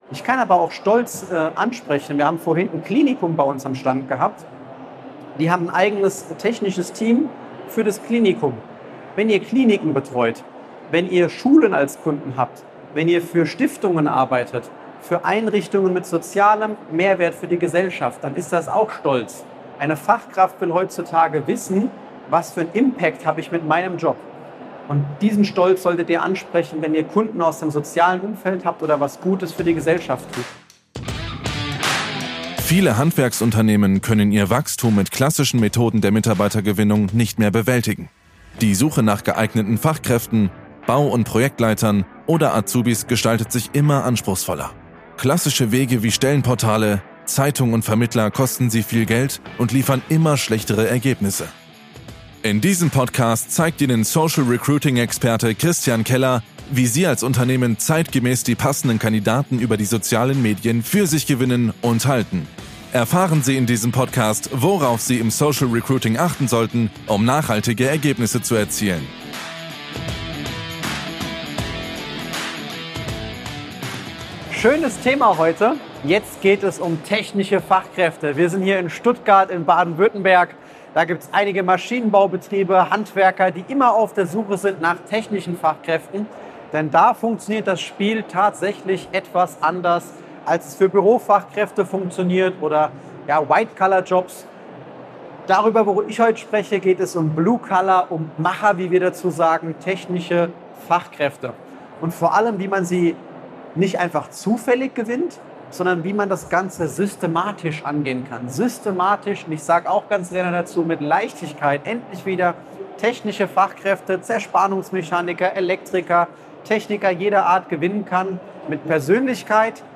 #113 - Systematisch technische Fachkräfte gewinnen im Handwerk & Fertigung (Vortrag)